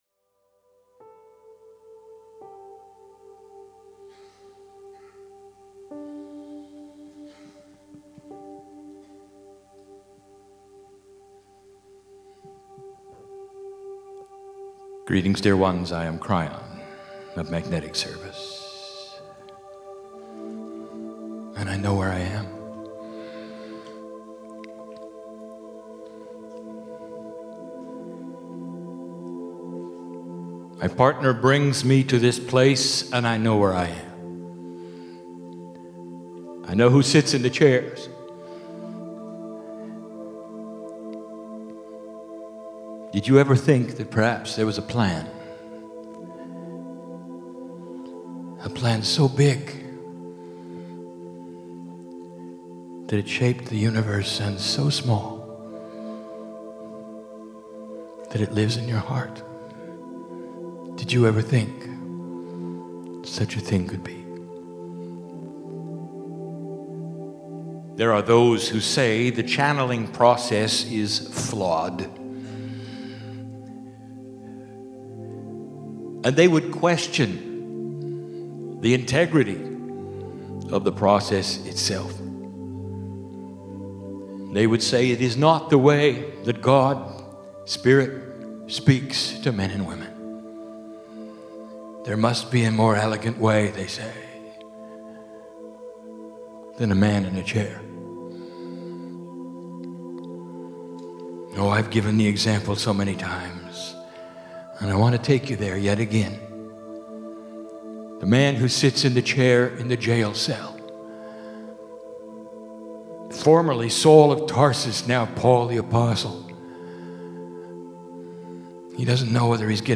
43 minute channelling session
INSTRUCTIONS: 64 megabytes 43 minutes High-quality Stereo - MP3 Filename: "Montreal 2011.mp3" PC - Right-click the left image to download the file.
This Channelling was given in English, but translated simultaneously into French for the French speaking provice of Canada.